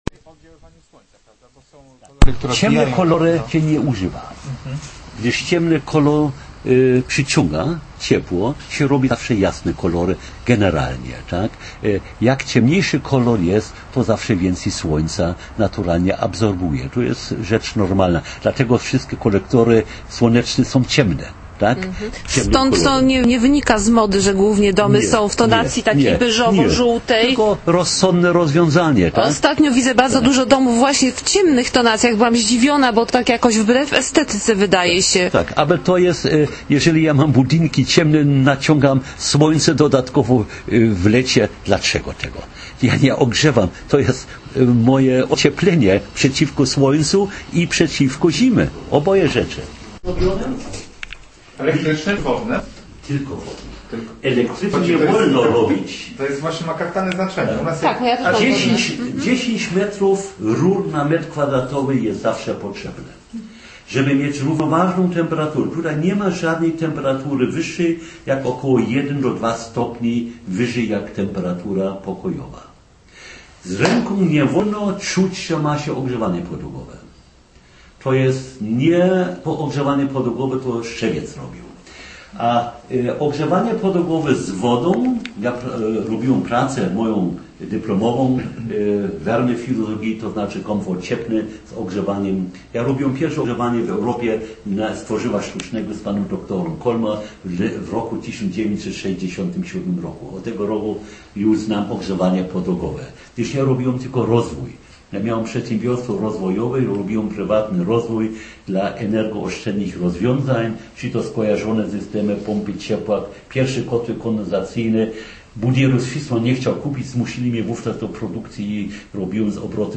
relacja ze zwiedzania domu pasywnego